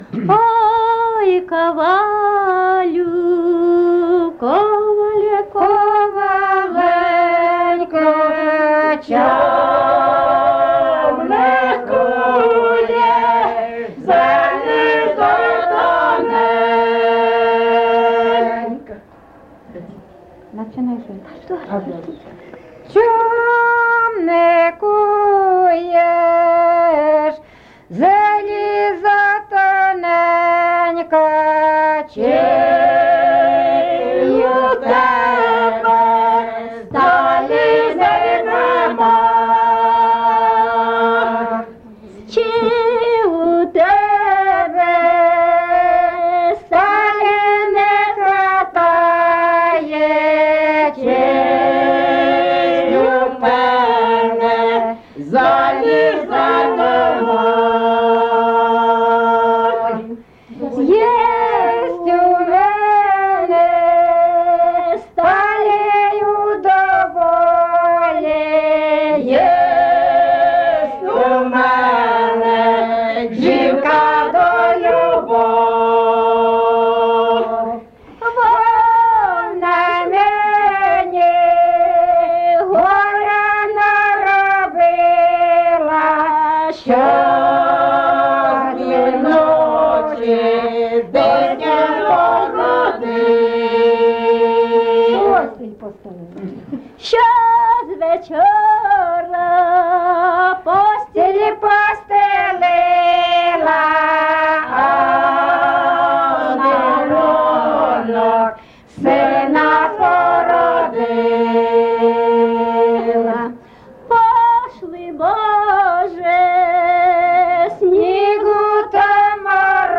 ЖанрПісні з особистого та родинного життя
Місце записус. Лиман, Зміївський (Чугуївський) район, Харківська обл., Україна, Слобожанщина